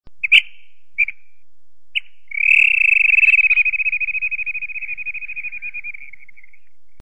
Flycatcher Bird-sound-HIingtone
flycatcher-bird.mp3